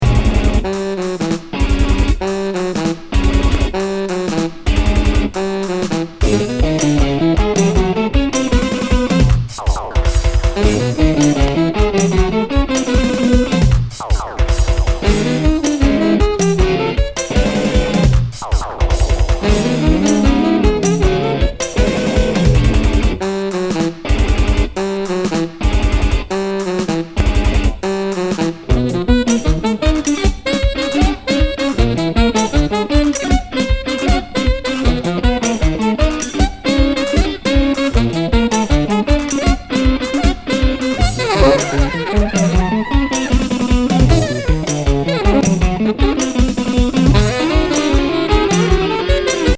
デンマーク産エクスペリメンタル・ジャズNW